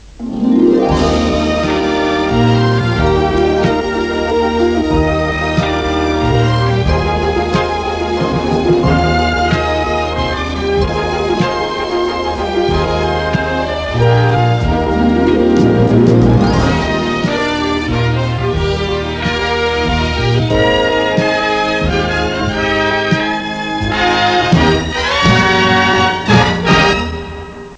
end theme music